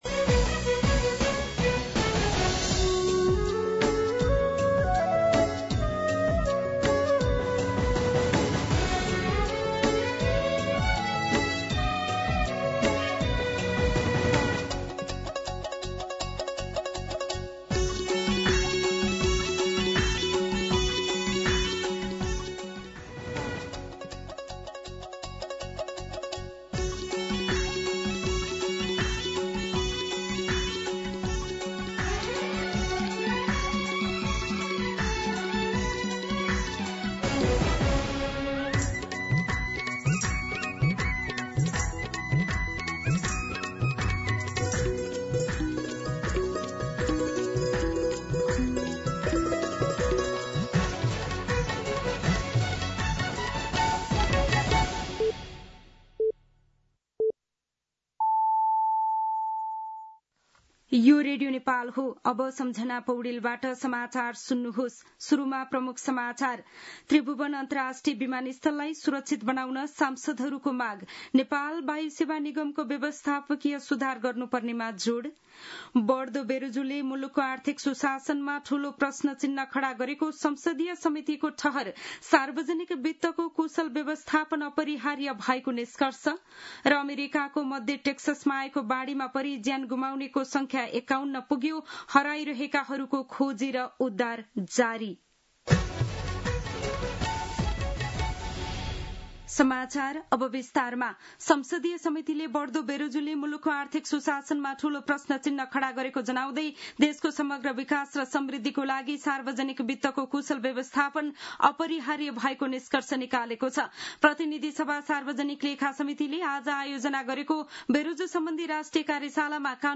दिउँसो ३ बजेको नेपाली समाचार : २२ असार , २०८२